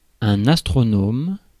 Ääntäminen
IPA : /ə.ˈstɹɑn.ə.mɚ/